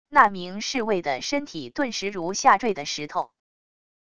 那名侍卫的身体顿时如下坠的石头wav音频生成系统WAV Audio Player